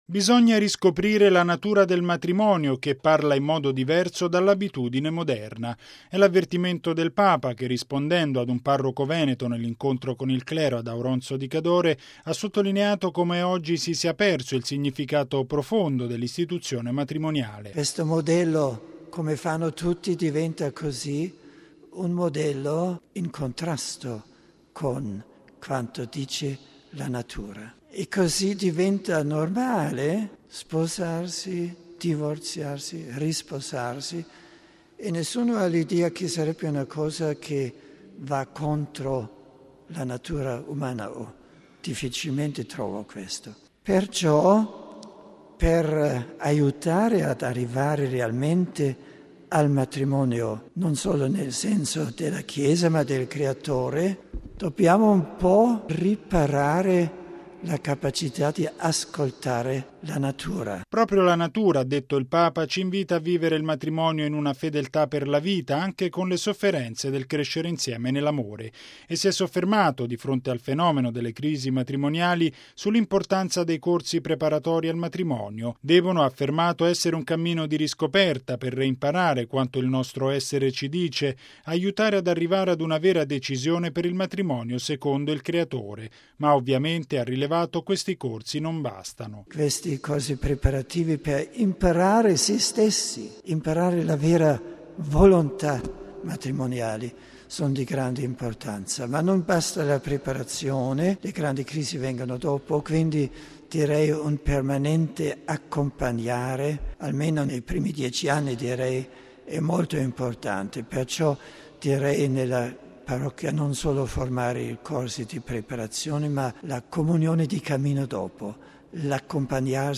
Anche in questi giorni, la famiglia è al centro dell’attenzione del Papa, mentre riecheggiano le sue parole al clero del Cadore sulla vera natura del matrimonio
Bisogna riscoprire la natura del matrimonio, “che parla in modo diverso” dall’abitudine moderna: è l’avvertimento del Papa, che rispondendo ad un parroco veneto nell’incontro con il clero ad Auronzo di Cadore, ha sottolineato come oggi si sia perso il significato profondo dell’istituzione matrimoniale: